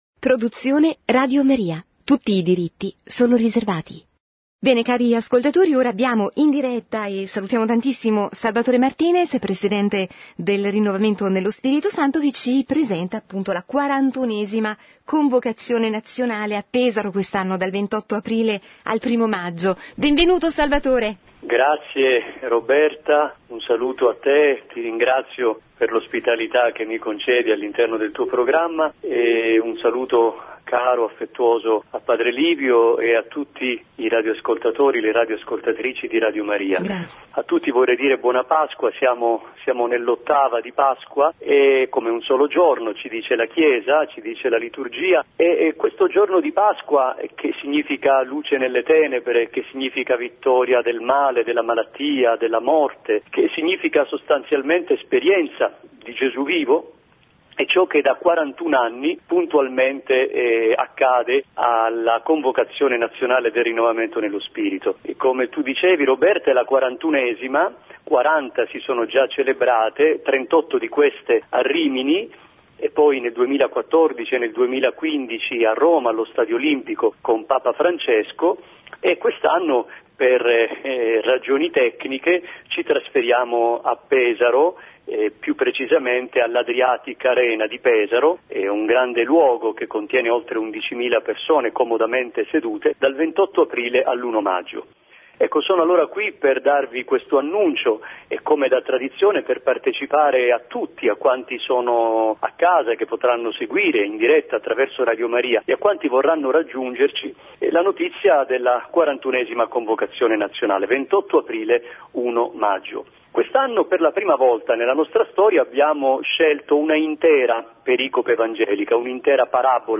martedì 3 aprile ha presentato in diretta dai microfoni di Radio Maria la 41ª Convocazione Nazionale dei Gruppi e delle Comunità del RnS